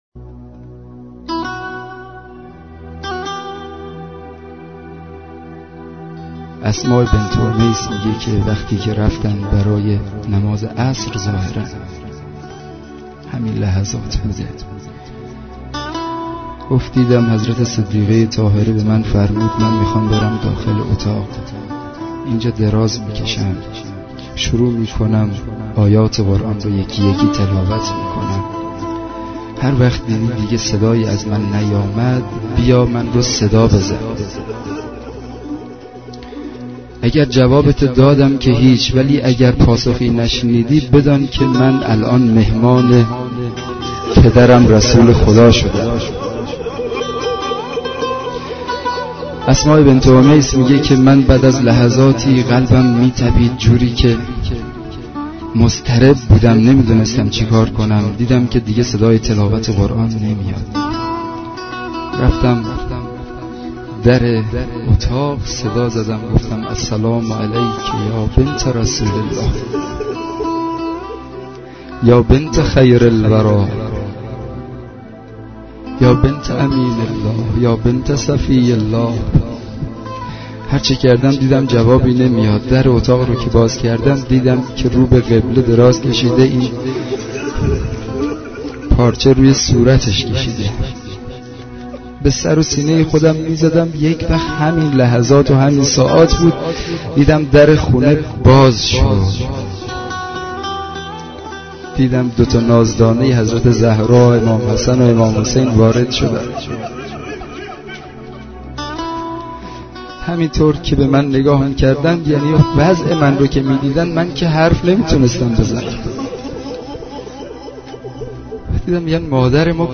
روضه حضرت زهرا(س) متاسفانه مرورگر شما، قابیلت پخش فایل های صوتی تصویری را در قالب HTML5 دارا نمی باشد.